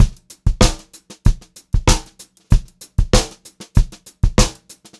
96POPBEAT2-R.wav